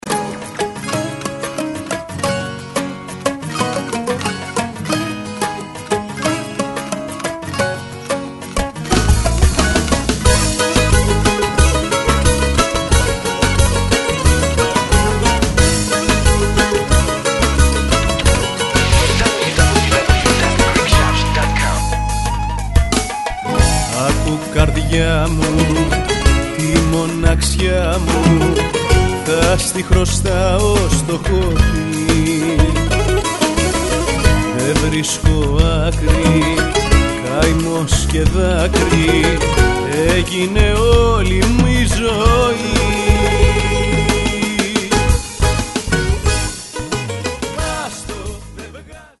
non-stop dance hits